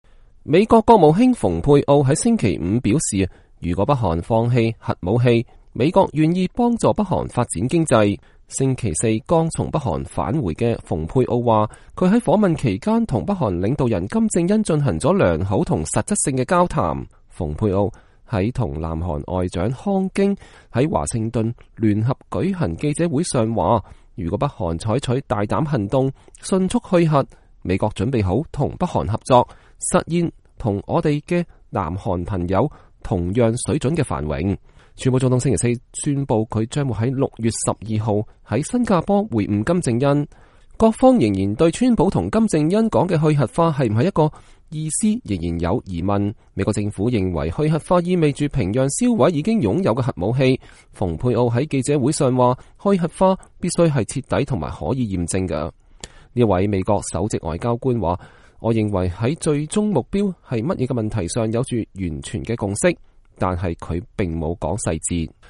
美國國務卿蓬佩奧和南韓外長康京和在華盛頓美國國務院會晤後聯合舉行記者會。（2018年5月11日）